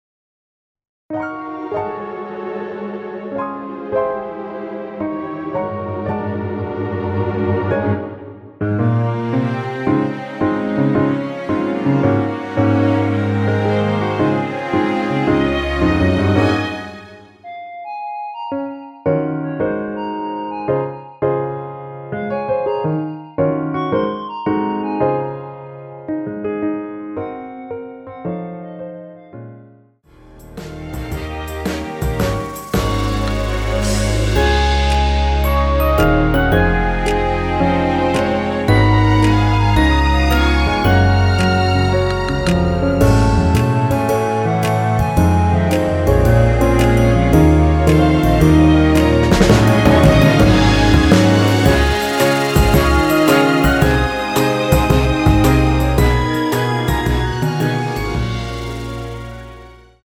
원키에서(-1)내린 멜로디 포함된 MR입니다.
Db
앞부분30초, 뒷부분30초씩 편집해서 올려 드리고 있습니다.